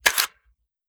Foley / 5.56 M4 Rifle - Magazine Unloading 003.wav